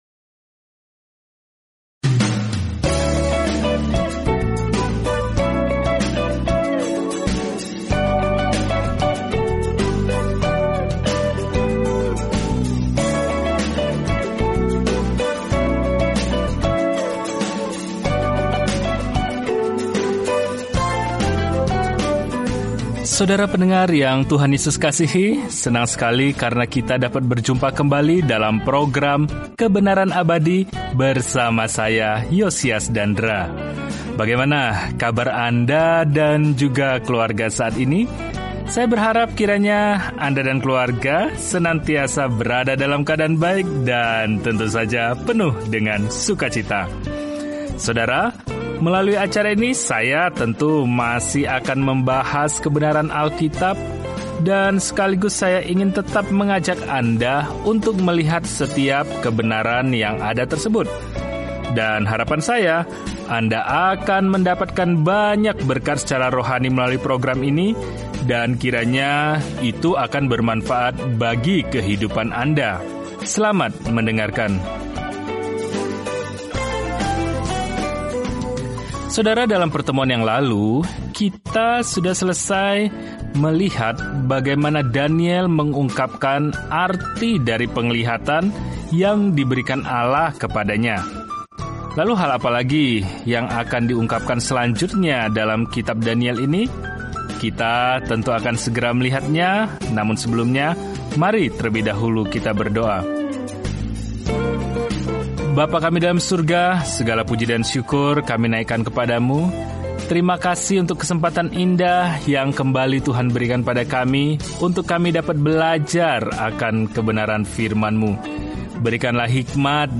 Firman Tuhan, Alkitab Daniel 10:1-10 Hari 24 Mulai Rencana ini Hari 26 Tentang Rencana ini Kitab Daniel merupakan biografi seorang pria yang percaya kepada Tuhan dan visi kenabian tentang siapa yang pada akhirnya akan memerintah dunia. Telusuri Daniel setiap hari sambil mendengarkan studi audio dan membaca ayat-ayat tertentu dari firman Tuhan.